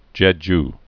(jĕj) also Che·ju (chĕ-)